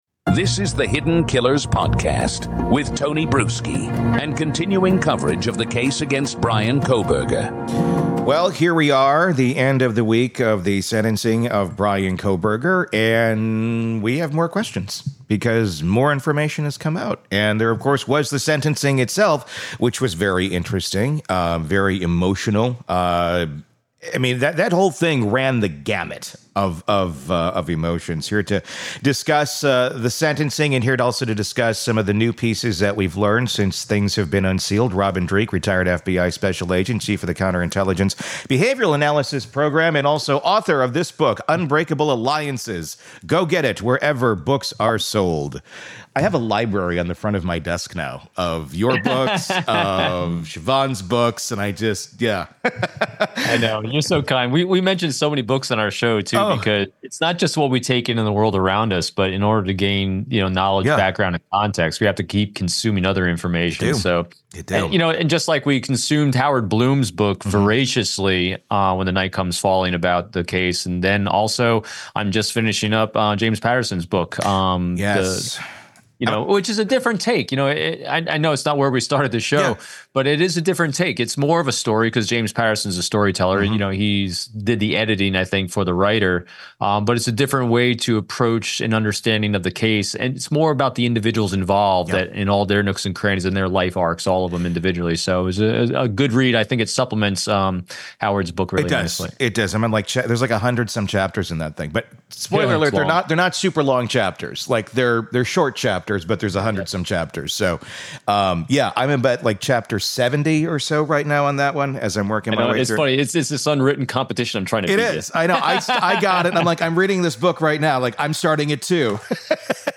In this special episode, we bring together four explosive conversations